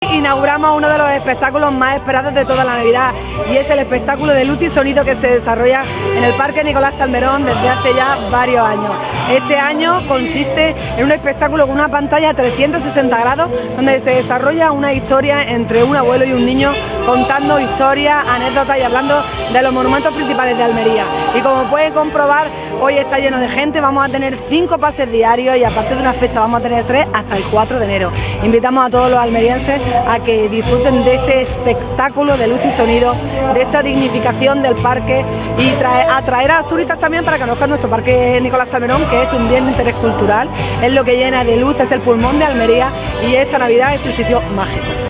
La alcaldesa, María del Mar Vázquez, asiste al estreno de este espectáculo, con luces, láser y pantalla 360º, y un total de 62 pases hasta el 4 de enero
TOTAL-ALCALDESA-INAUGURACION-ESPECTACULO-ALMERIA-CIUDAD-DE-LA-LUZ.mp4.wav